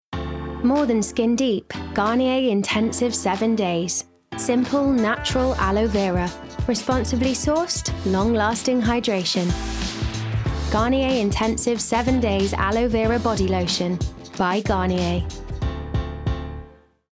20/30's Welsh/Neutral,
Bright/Youthful/Fun